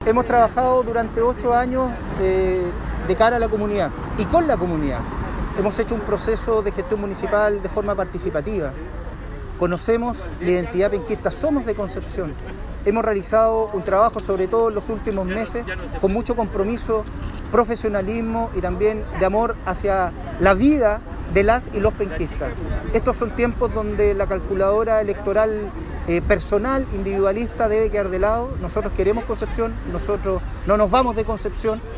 Desde el Parque Ecuador, en el hito que marca el nombre de la ciudad, y acompañado por colaboradores y representantes de la sociedad civil, Álvaro Ortiz, presentó oficialmente su candidatura a la reelección por la alcaldía de Concepción.